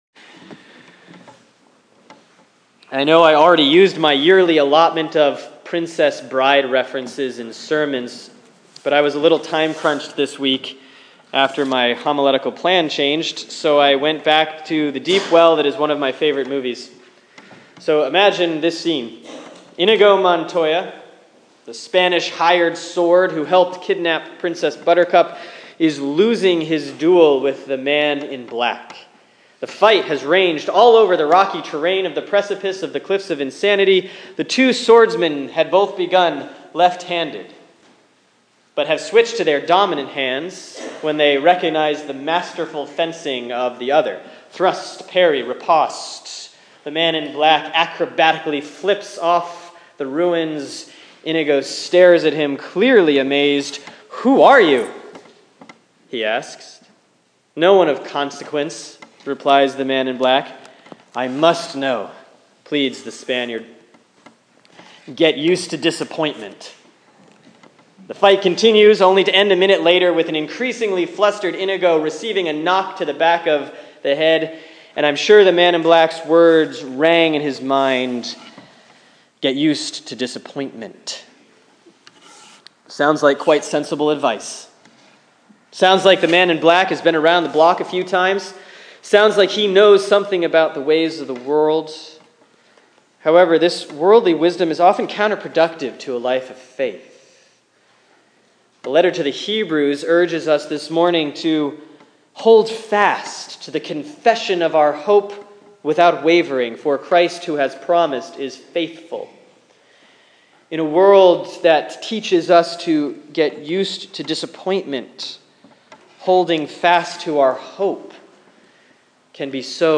Sermon for Sunday, November 15, 2015 || Proper 28B || Hebrews 10:11-25